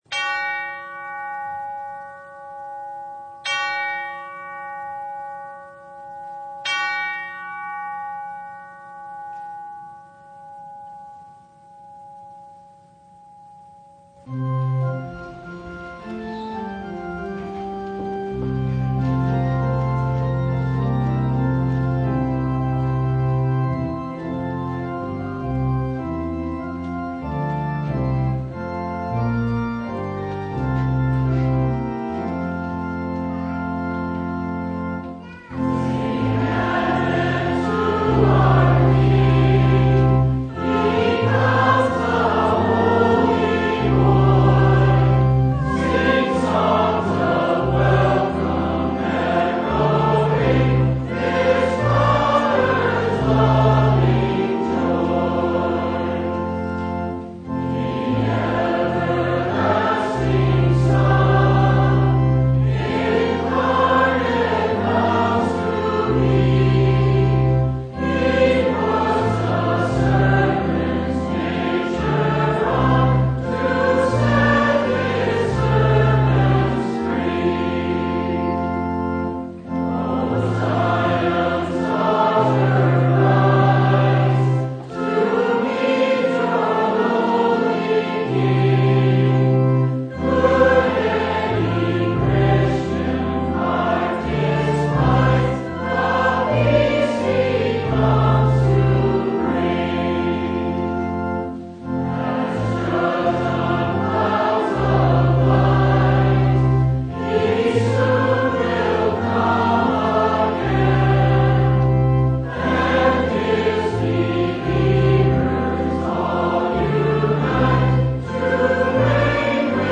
Download Files Notes Bulletin Topics: Full Service « No Comparison!